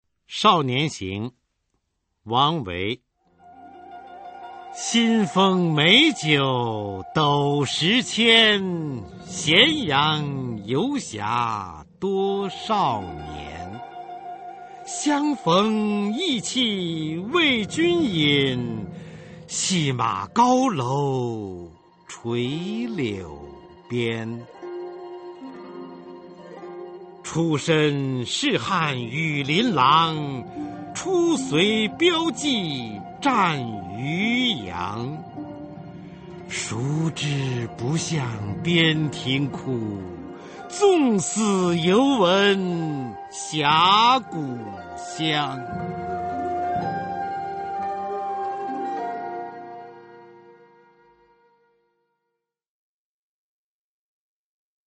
[隋唐诗词诵读]王维-少年行（男） 配乐诗朗诵